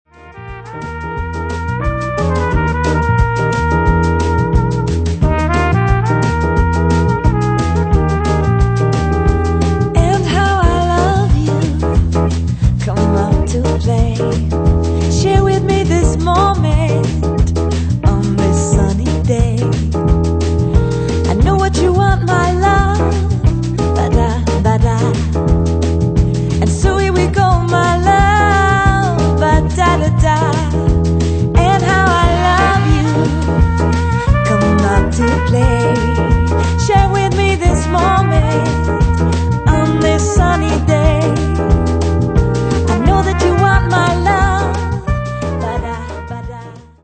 ist ein zeitloser Clubsound